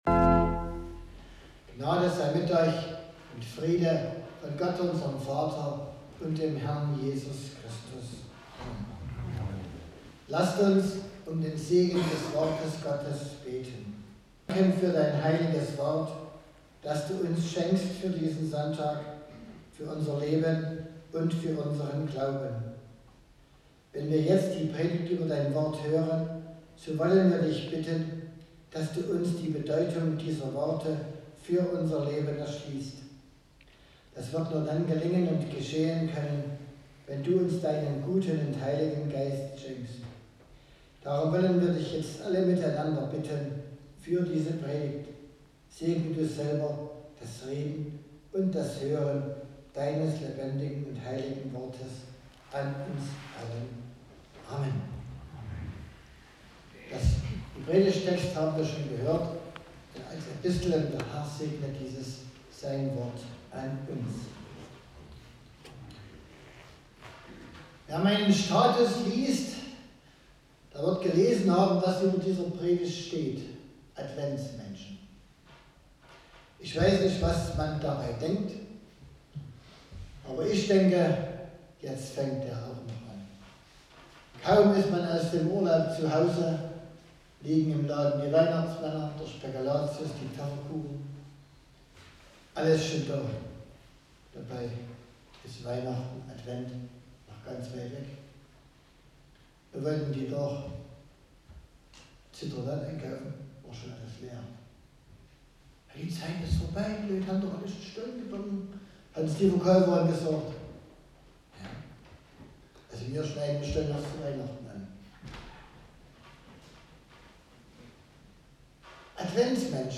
Gottesdienstart: Predigtgottesdienst